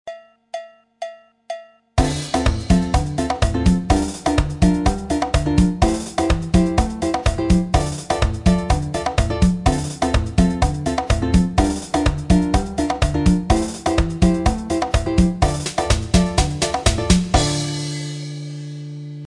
Variante 1 figure guitare candomble 2 avec section rythmique guitare baião 2.